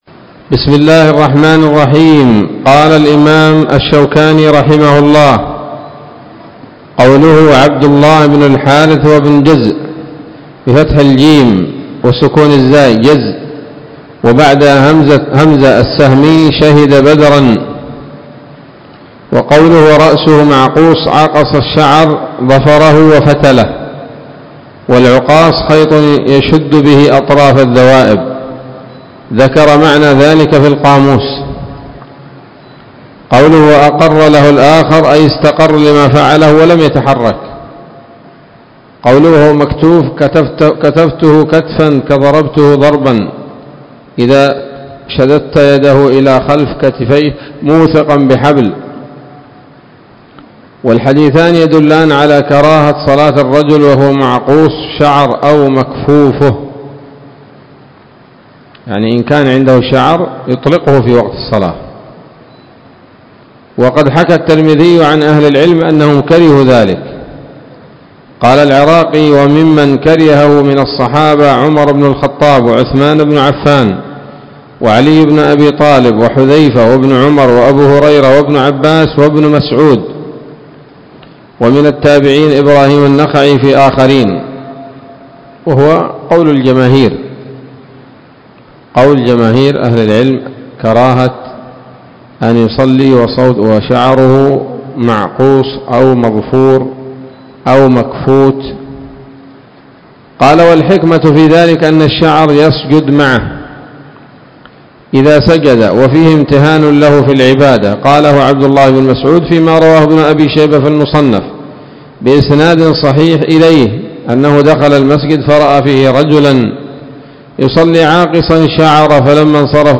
الدرس السابع عشر من أبواب ما يبطل الصلاة وما يكره ويباح فيها من نيل الأوطار